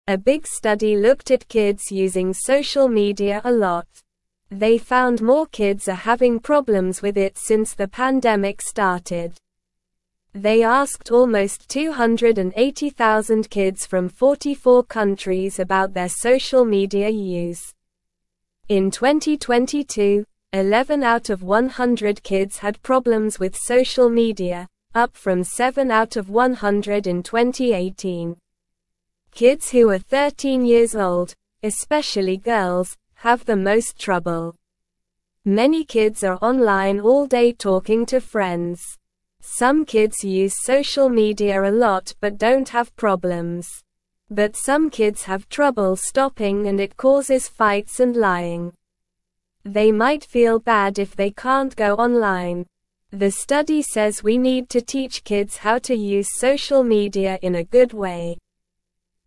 Slow
English-Newsroom-Beginner-SLOW-Reading-Kids-Using-Social-Media-More-Since-Pandemic-Started.mp3